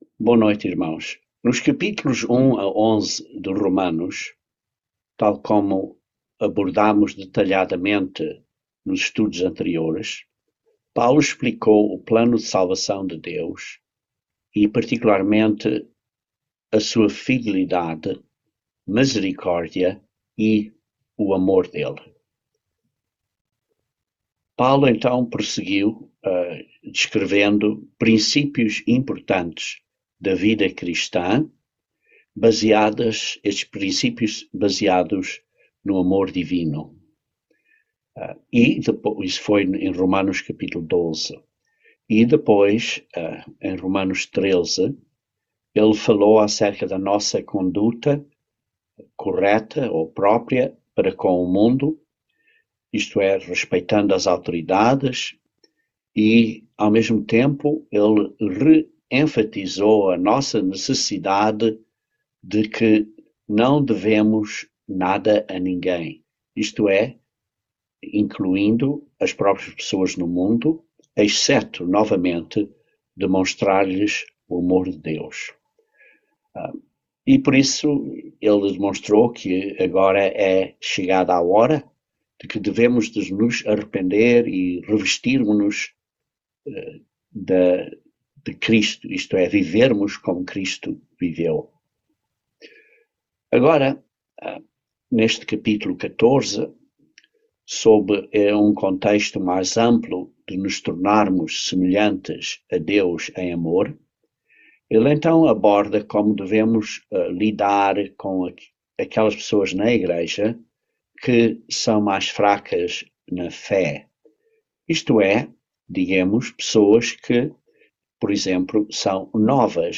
Romanos 14:1-23 - Estudo Bíblico